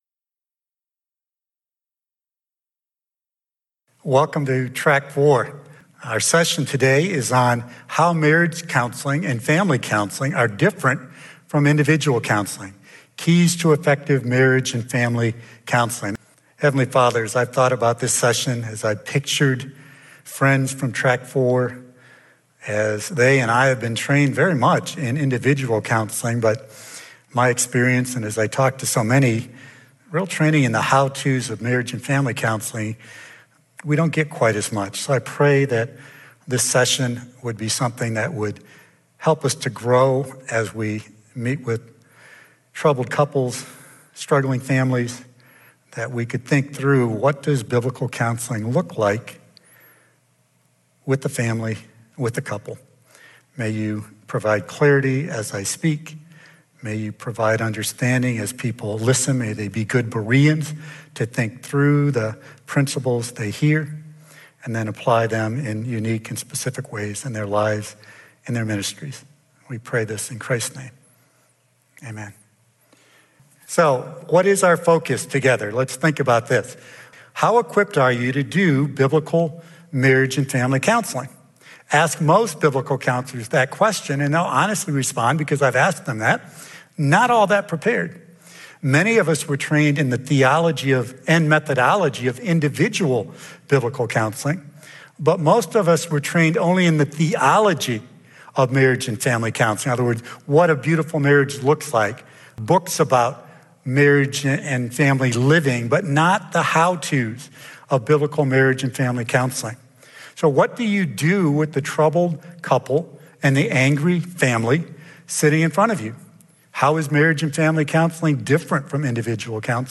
This is a session from the Biblical Counseling Training Conference hosted by Faith Church in Lafayette, Indiana.
About the speaker: